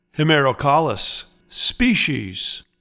Pronounciation:
Hem-er-o-CAL-lis SPEE-shes